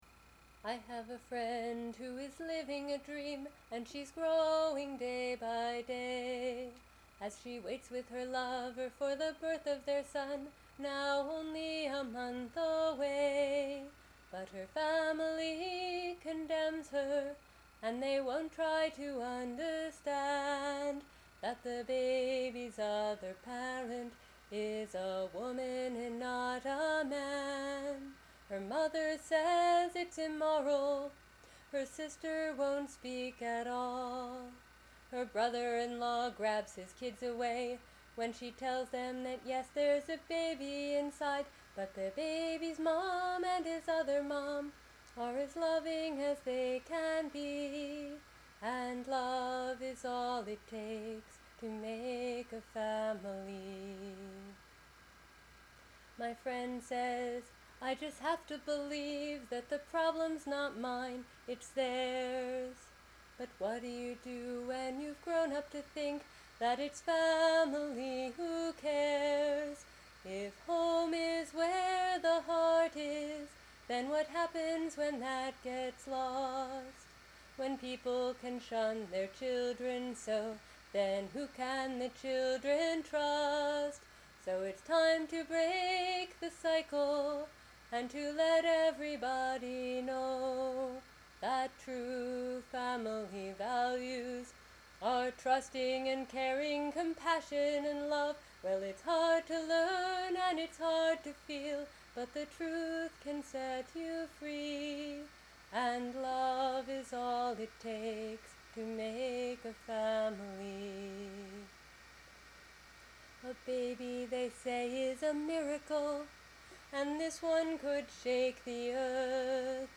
There were some technical difficulties with the gain, for which I'm sorry.